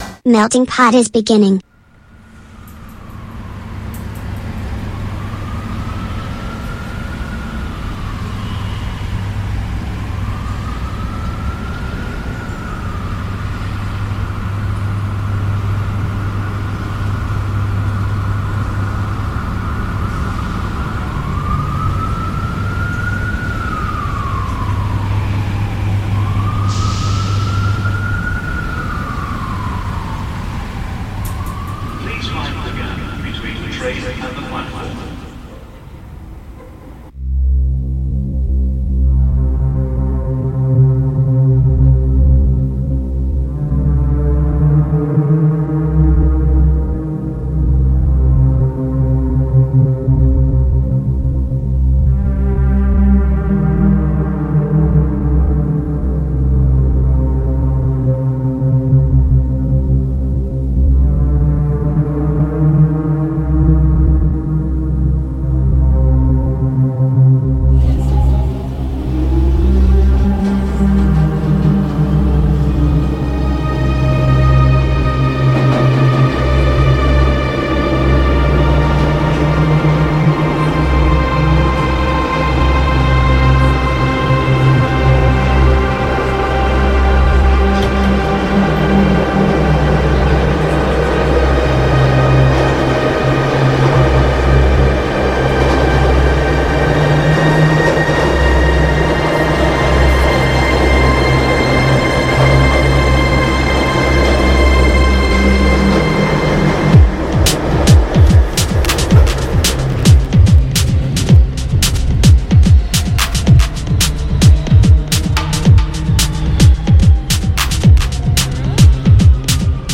Non possono mancare gli ospiti con l’intervista telefonica alla band romana dei Girodido’, che ci hanno presentato il loro nuvo EP ed il oro spettacolo Canta Roma!